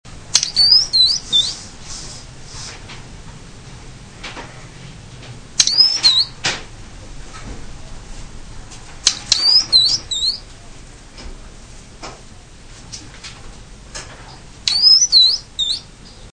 さえずりのコーナー（オオルリ編）
野鳥のホームページでアップされている方のさえずりとは違い、せわしないですね。
よそ行き 40KB 我が家へ来た時のさえずりです。